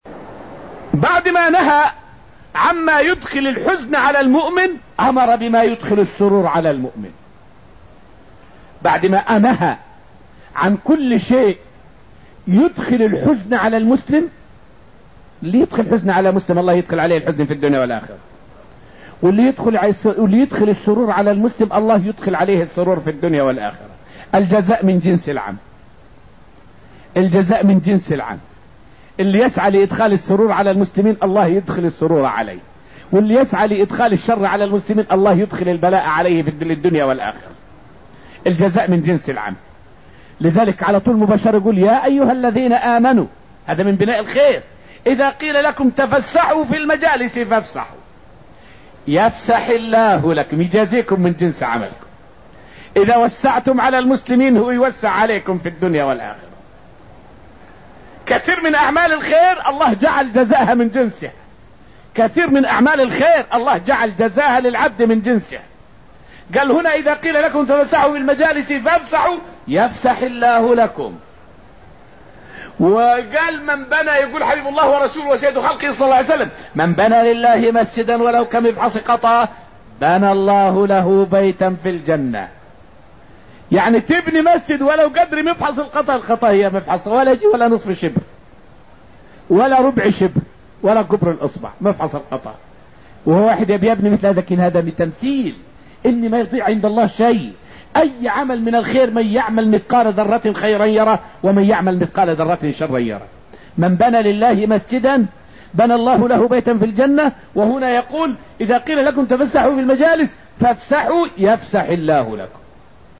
فائدة من الدرس الأول من دروس تفسير سورة المجادلة والتي ألقيت في المسجد النبوي الشريف حول الإيلاء من صور إيذاء المرأة في الجاهلية.